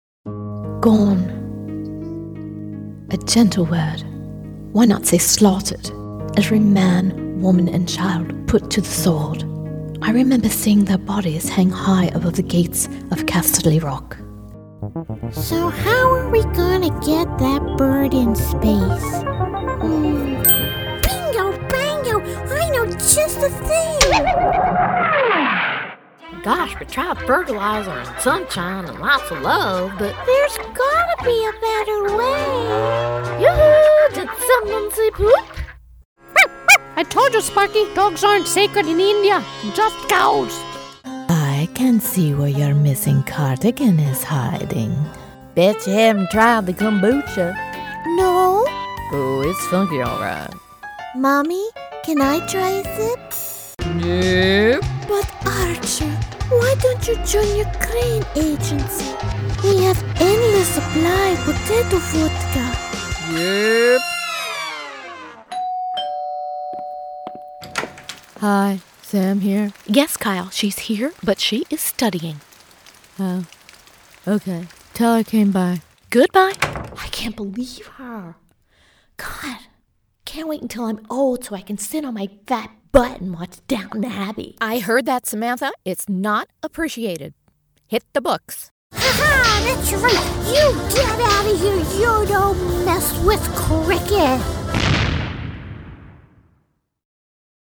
US American, female voice
mid-atlantic
Sprechprobe: eLearning (Muttersprache):